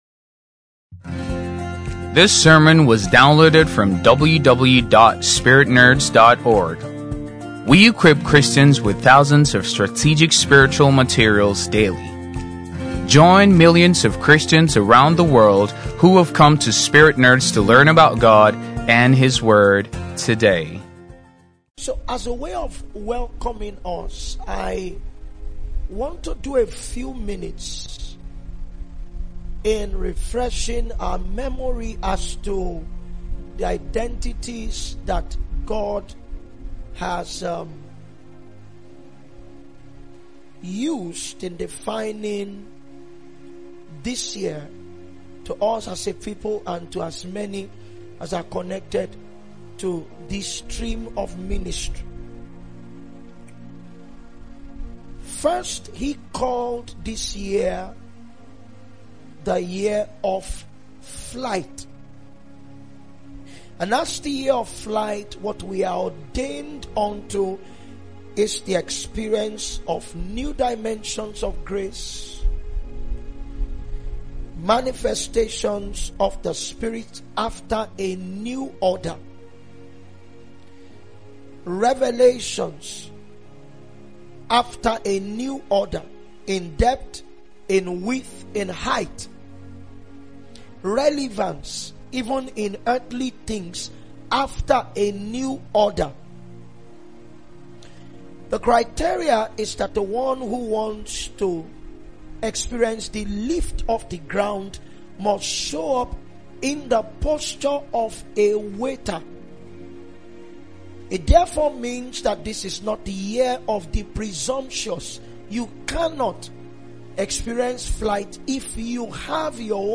Download Gospel Messages/Sermons titled “The Law OF Servitude” These are the kinds of sermons you will listen to that will transform your life greatly.
Remain blessed as you listen to the wisdom from God preached through his servant.